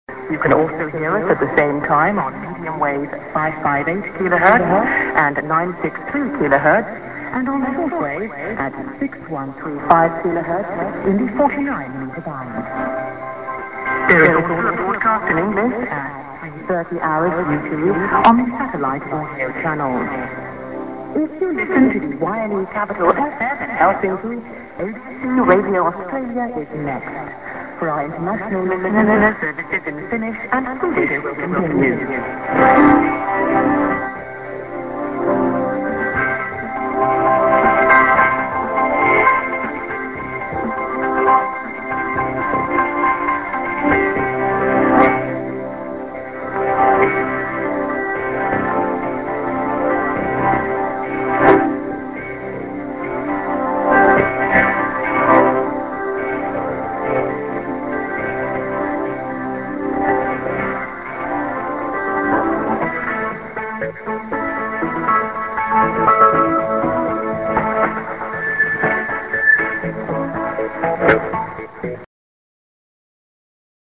Eng.End SKJ(women)->music